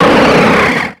Cri de Relicanth dans Pokémon X et Y.